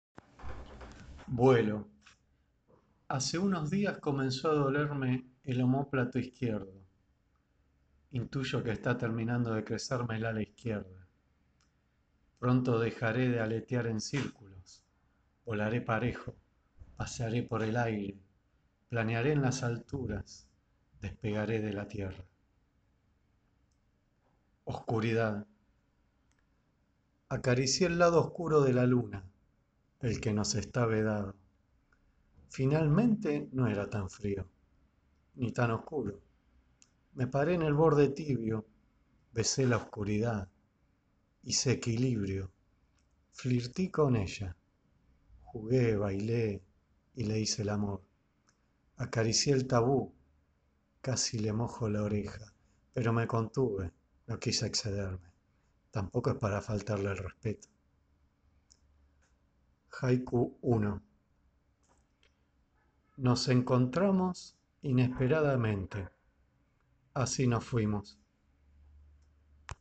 Audio en la voz el autor click abajo en Link de Descarga.